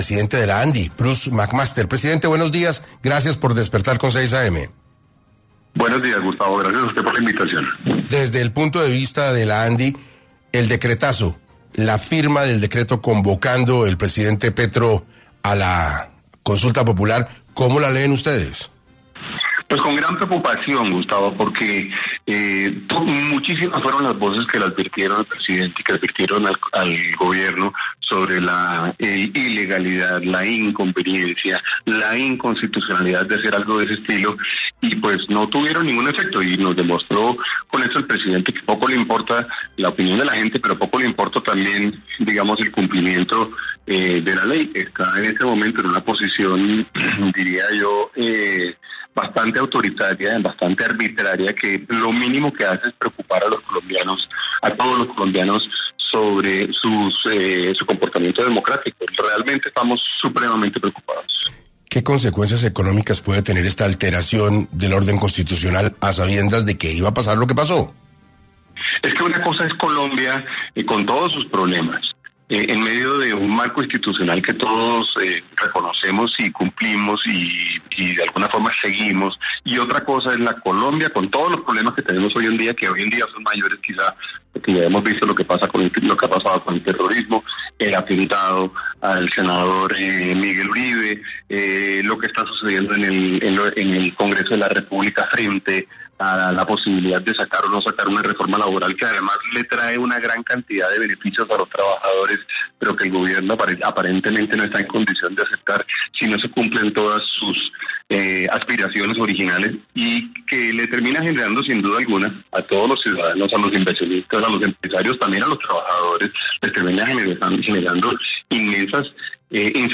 En declaraciones a 6AM de Caracol Radio, Mac Master señaló que numerosas voces advirtieron al Gobierno sobre la ilegalidad e inconveniencia de tal medida, pero estas advertencias fueron ignoradas.